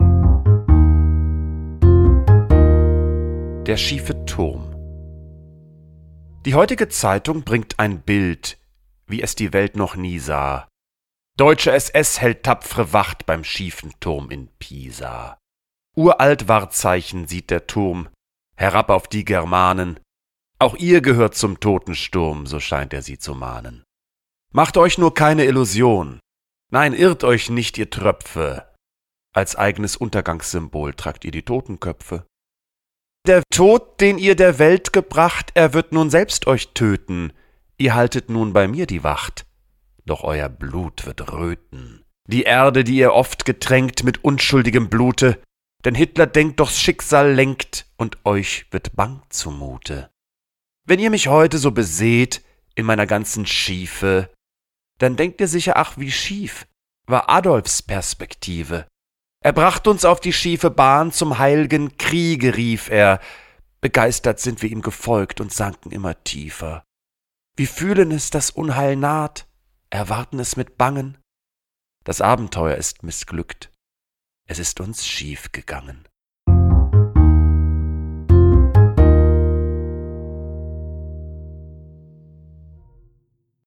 Musik: Kristen & Schmidt, Wiesbaden
Jan-Boehmermann__TURM_mit-Musik_raw.m4a